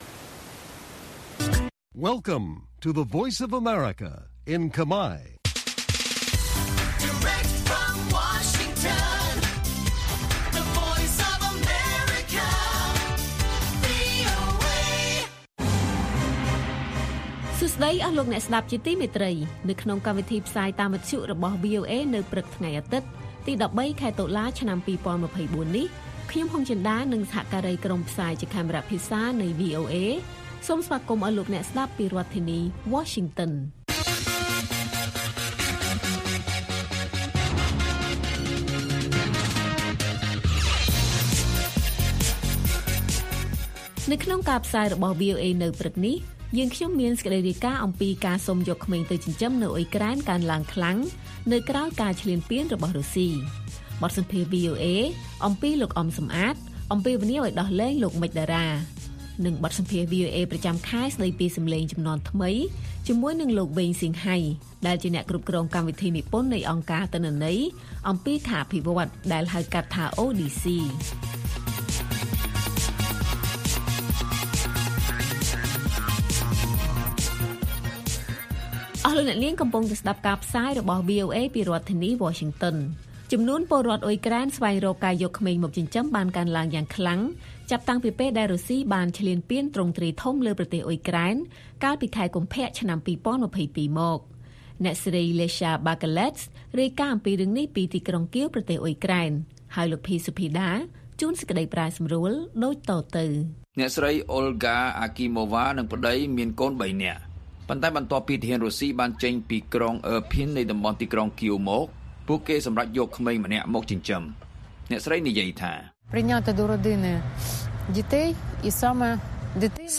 ព័ត៌មានពេលព្រឹក
ព័ត៌មាននៅថ្ងៃនេះមានដូចជា ការសុំយកក្មេងទៅចិញ្ចឹមនៅអ៊ុយក្រែនកើនឡើងខ្លាំងនៅក្រោយការឈ្លានពានរបស់រុស្ស៊ី។ បទសម្ភាសន៍ VOA